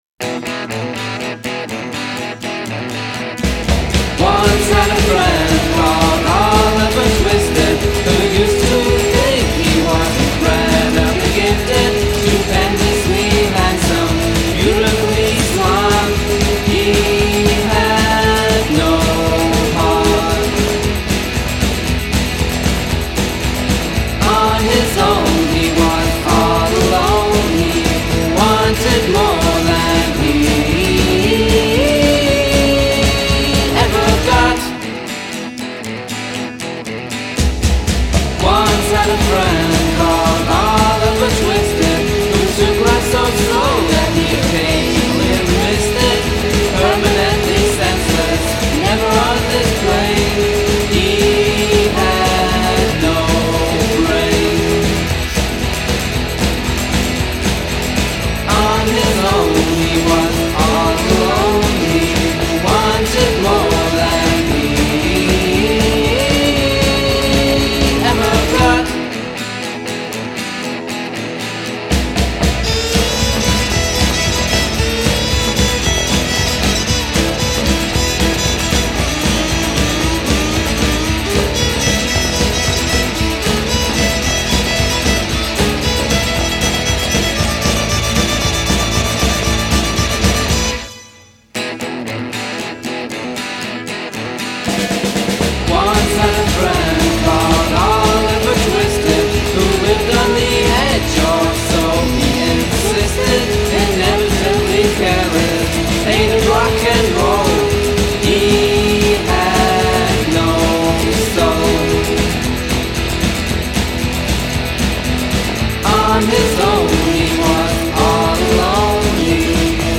Genre: Indie